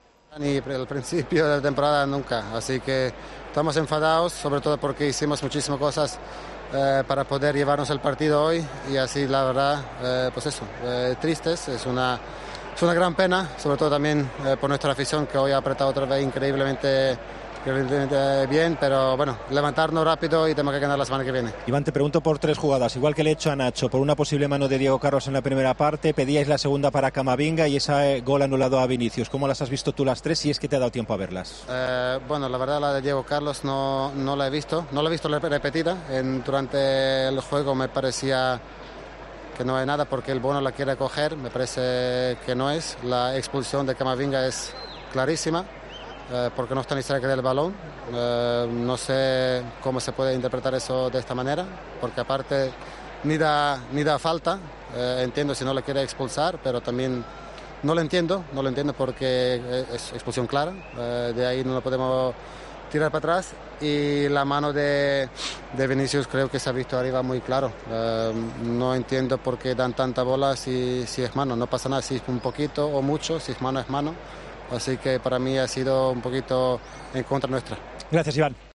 Rakitic habló en Movistar de las polémicas del partido, en el que los sevillistas perdieron 2-3 ante el Real Madrid.